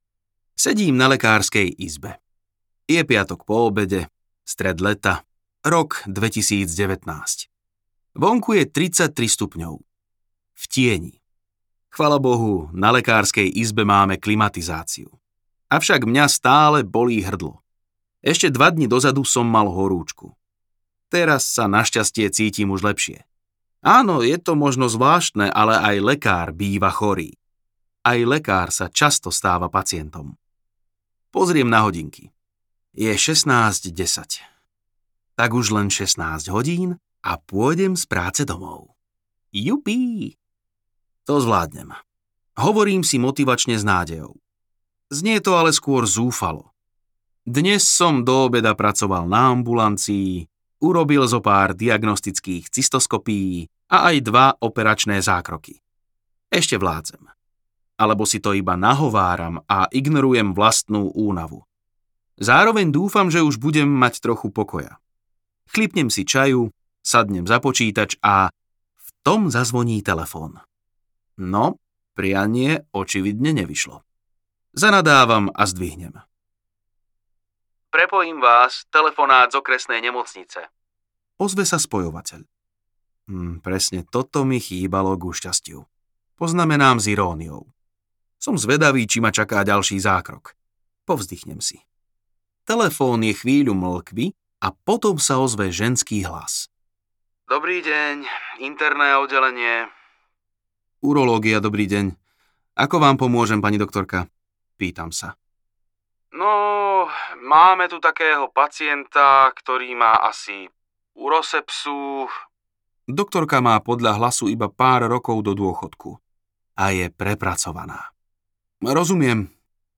Urostorky audiokniha
Ukázka z knihy